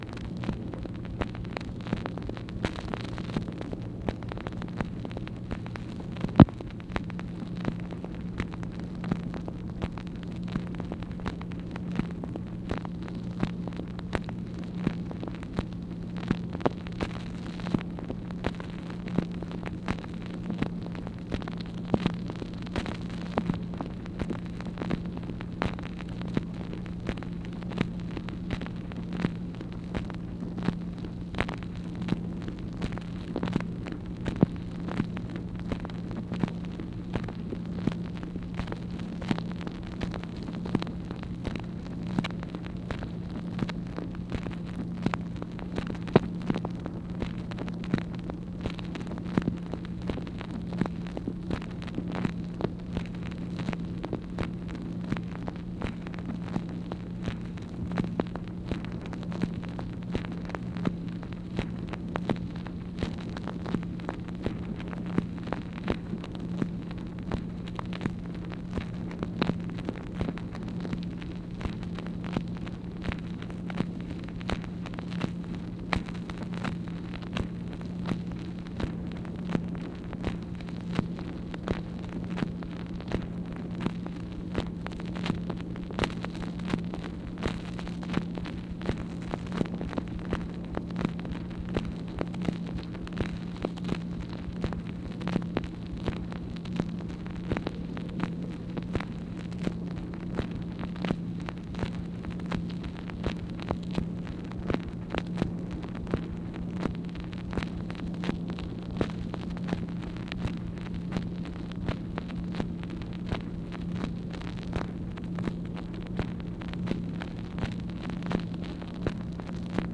MACHINE NOISE, April 1, 1964
Secret White House Tapes | Lyndon B. Johnson Presidency